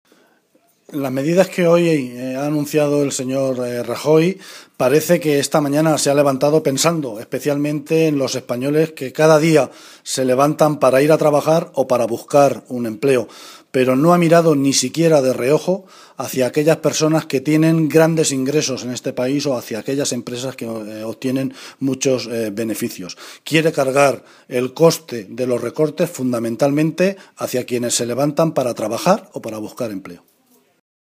José Luis Martínez Guijarro, portavoz del Grupo Parlamentario Socialista
Cortes de audio de la rueda de prensa